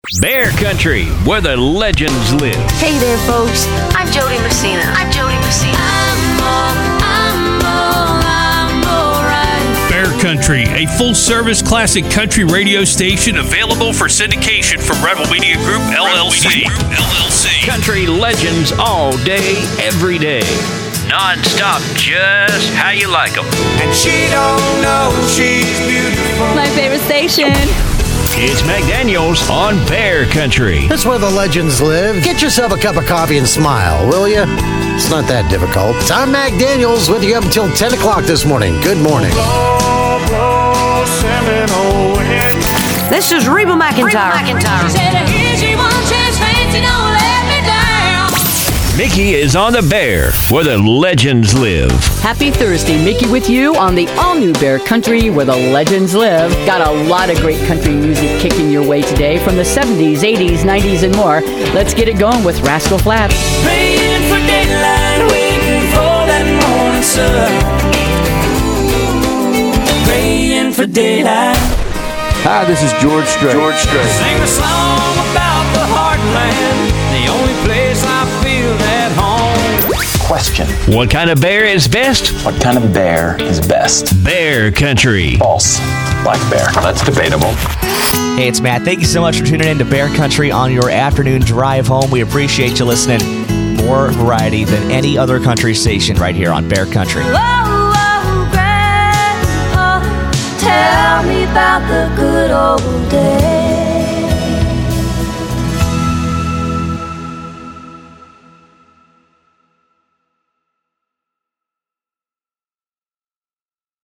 Classic Country